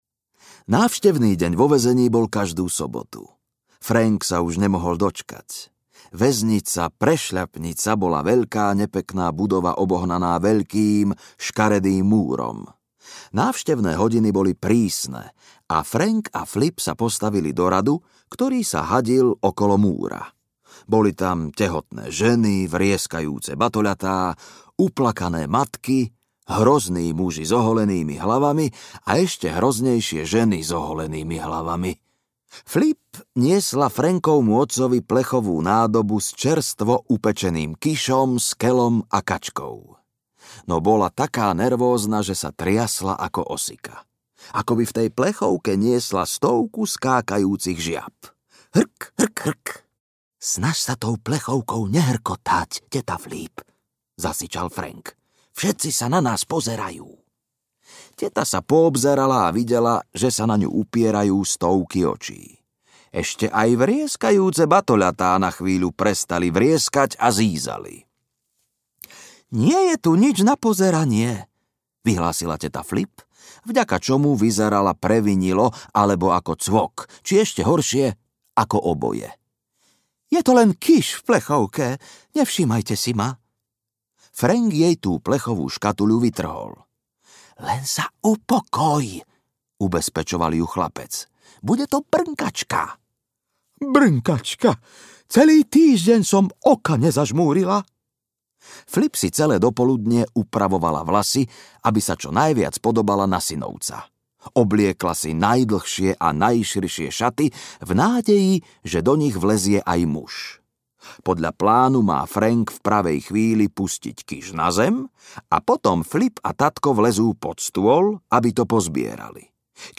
Tatko za mrežami audiokniha
Ukázka z knihy